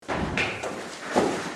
Sound Effects
Person Stumbled Sound